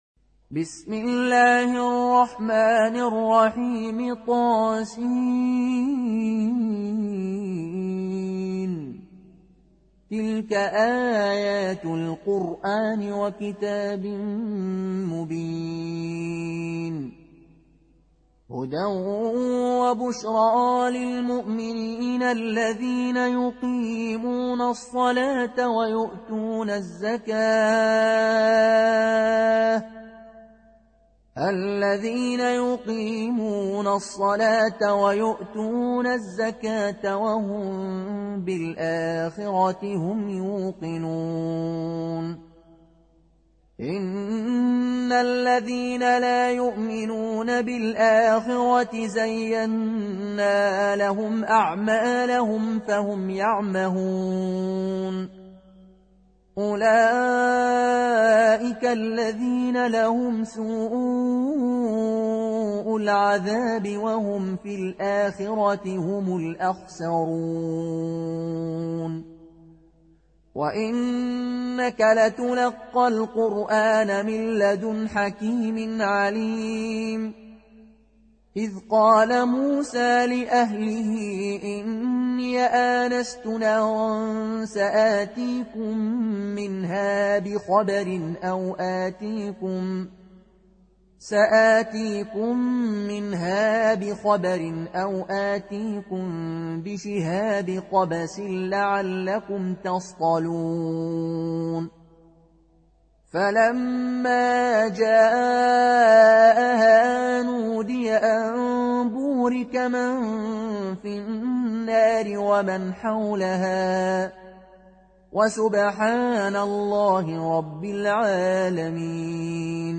برواية قالون عن نافع